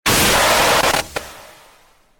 BlitzJumpscareNew.mp3